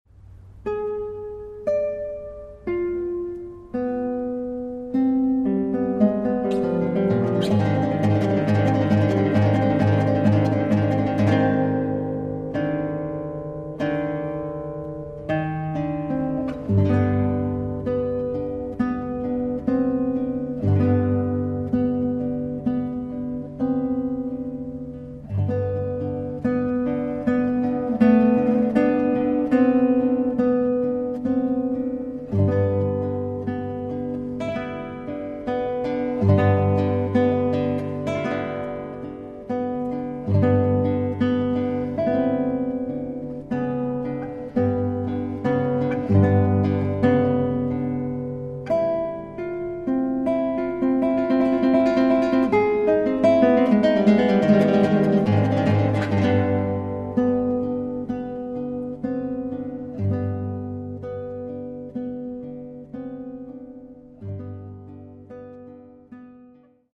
New Zealand classical guitarist composer